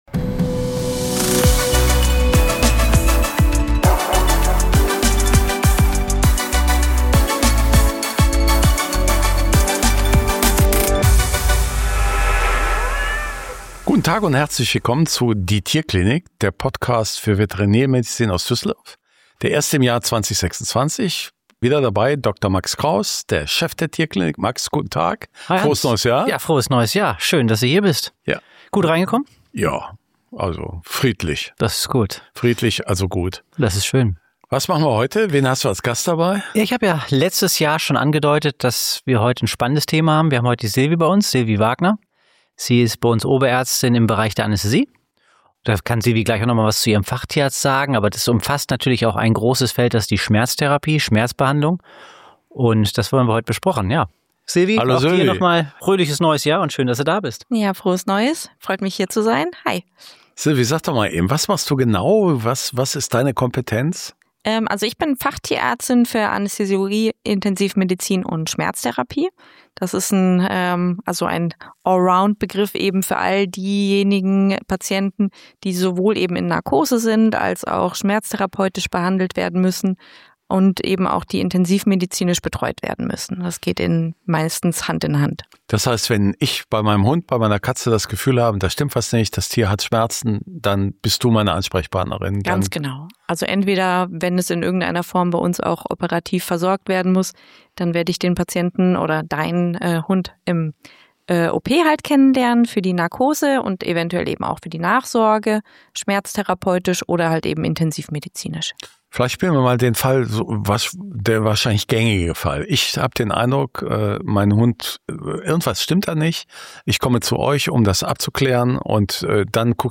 Freut euch auf ein intensives, praxisnahes Gespräch für alle, die ihren Vierbeinern wirklich helfen wollen.